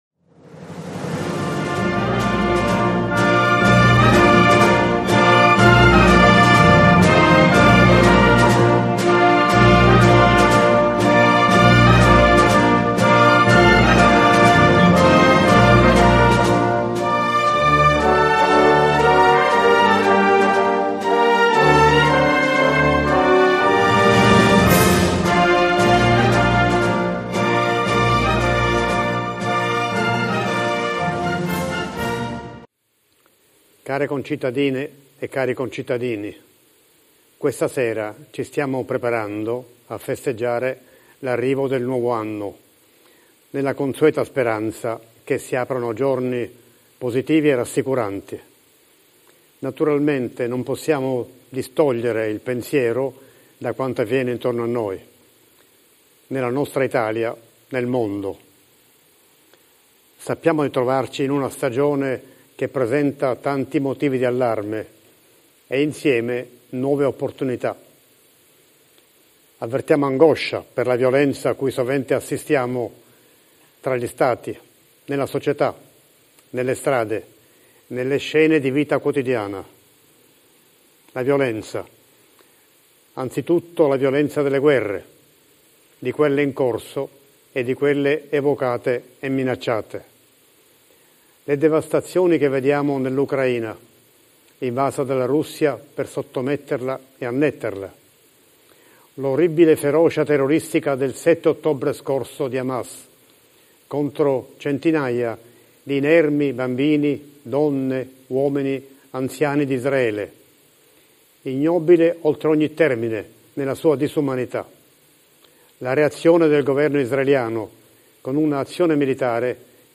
Gli auguri di Natale 2024 del cardinale Roberto Repole, arcivescovo di Torino e vescovo di Susa 2024-12-23 Sabato 23 dicembre 2024 il cardinale Roberto Repole, arcivescovo di Torino e vescovo di Susa, ha incontrato giornalisti e operatori dei mass media per il tradizionale augurio di Natale a loro e alla città.